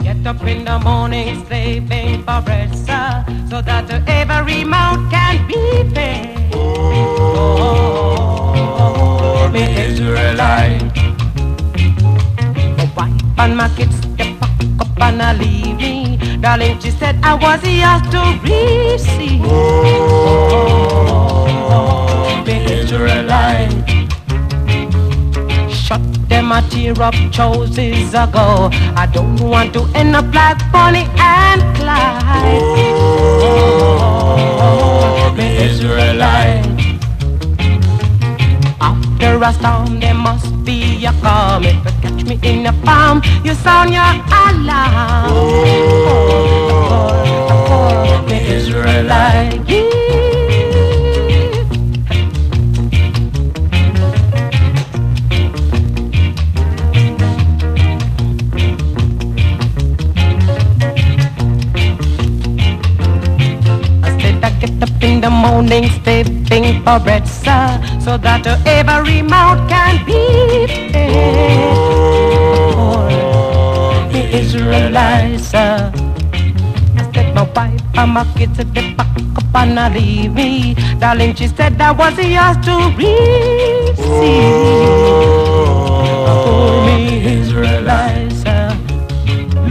SKA/ROCKSTEADY / EARLY REGGAE
切なさ漂うメロディと憂いを帯びたヴォーカルが胸を打つラヴリー・ロックステディ
ソウルフルなグルーヴと軽快なビートが心地よい最高トラック